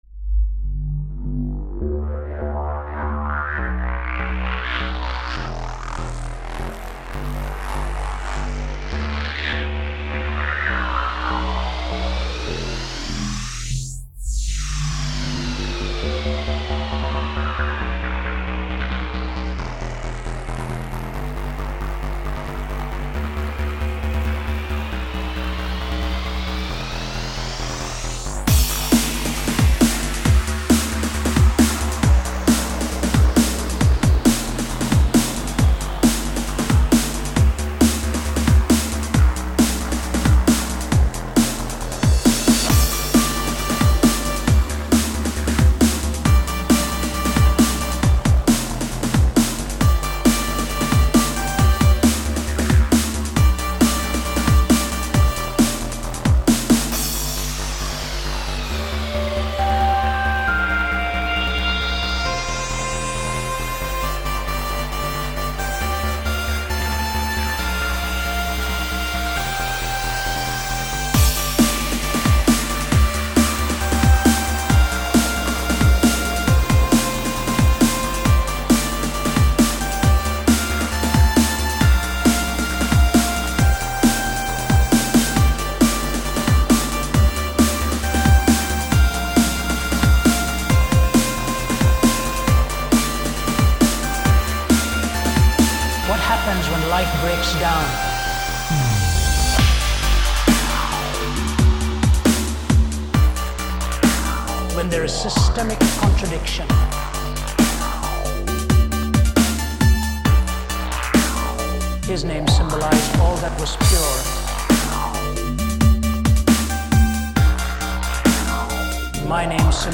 Infuse (breakbeat/dubstepmix
Specially with break-beats and vocals
breakbeat dubstep breakdown